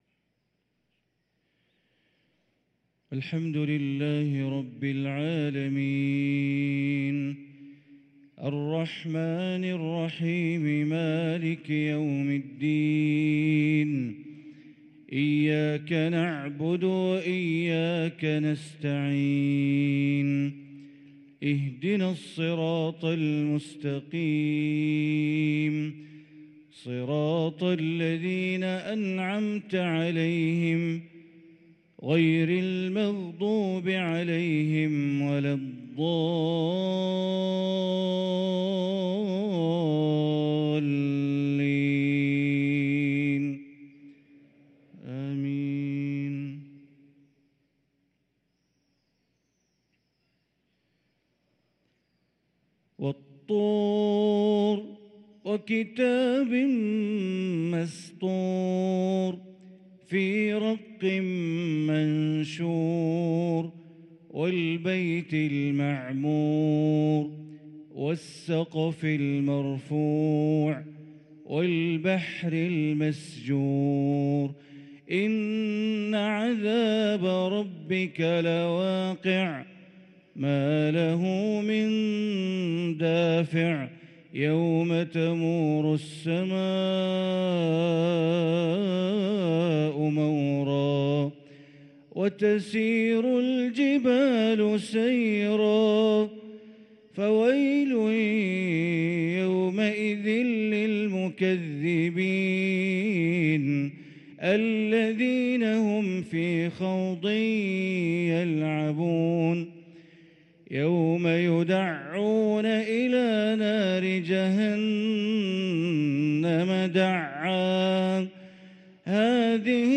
صلاة الفجر للقارئ بندر بليلة 19 ربيع الأول 1444 هـ